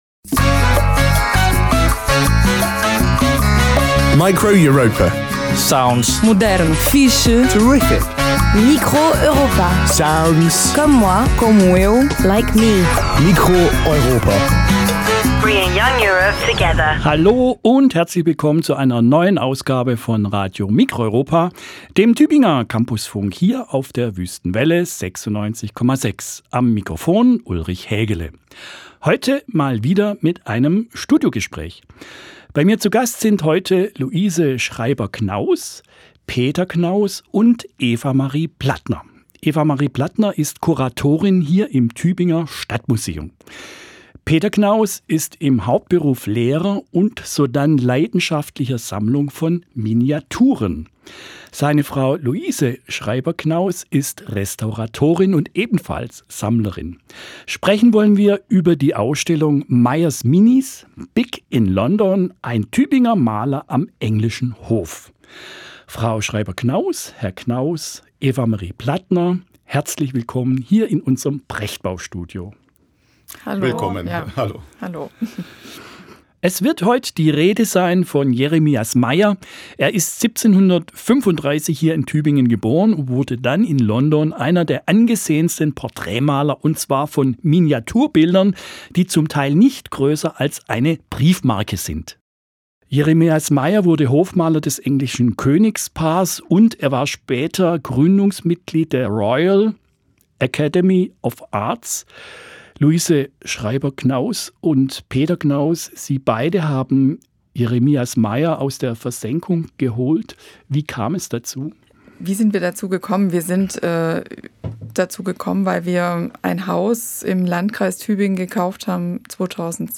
Studiogespräch über die Ausstellung Meyers Minis im Tübinger Stadtmuseum
Form: Live-Aufzeichnung, geschnitten